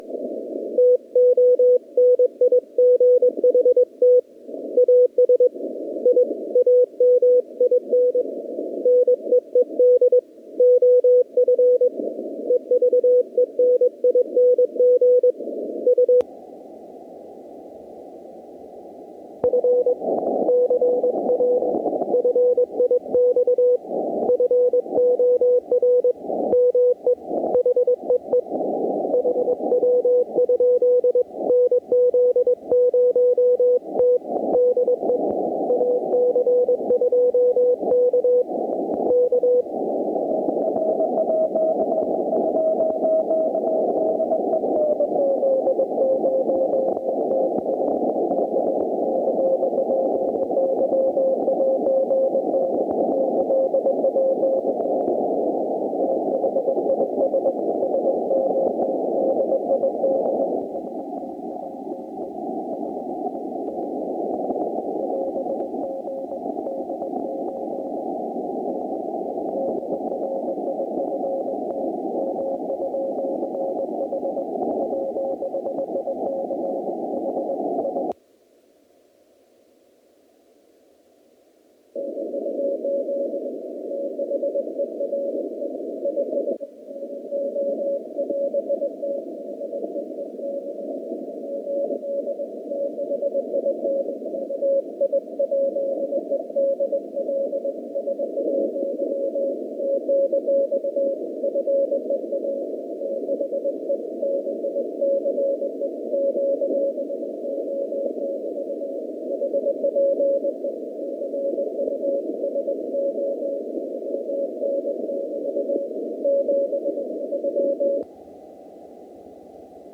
Also, the K4 has better LF audio response, which may account for the wider-sounding passband.
> Recorded off rear headphone jack using an MK2R+ USB Sound Card.
> 01:01 - 01:21  K4 with NR engaged and adjusted for best copy ("Level 3")